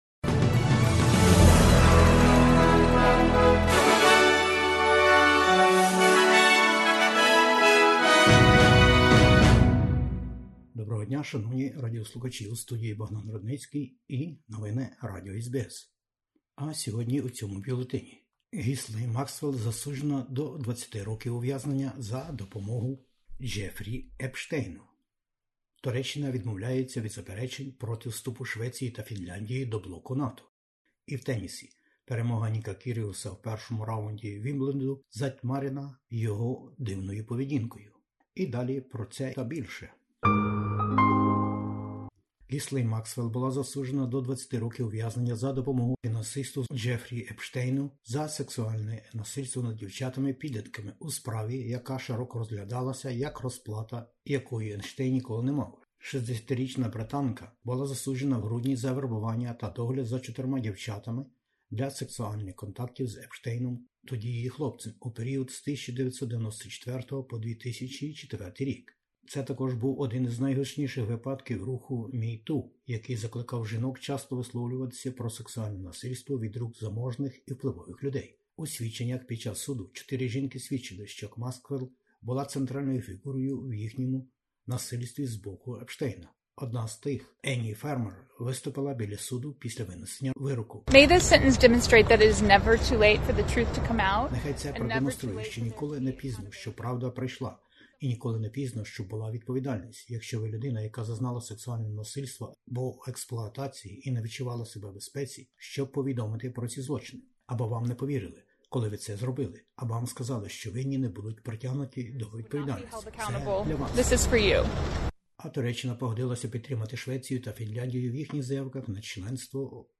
Бюлетень SBS новин українською мовою. Міністерка закордонних справ Австралії продовжує свою подорoж країнами реґіону. Війна в Україні та заклики до світу про допомогу Україні у війні з Росією братів Кличків.